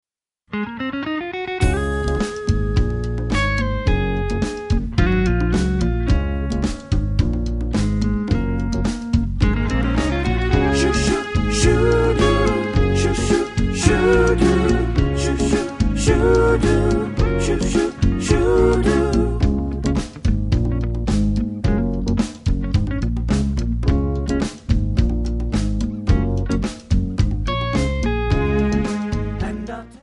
D
Backing track Karaoke
Pop, 1970s